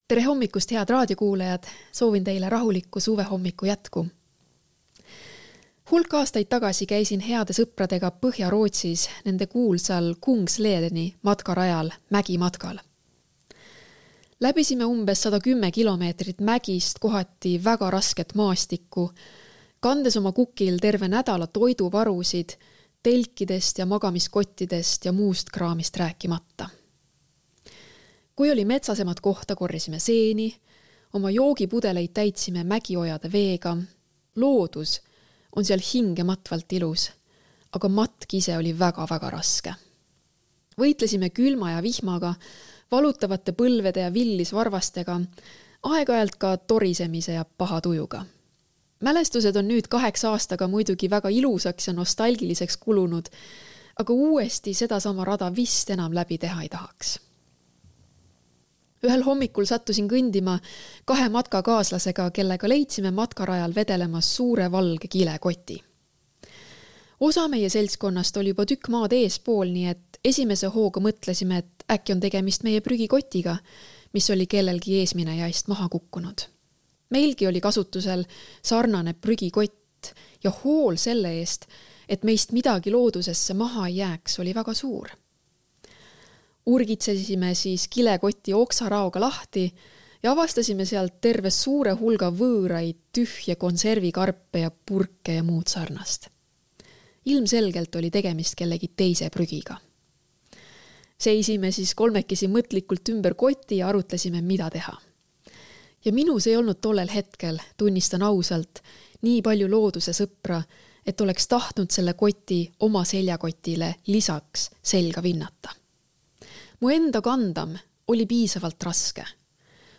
Hommikupalvused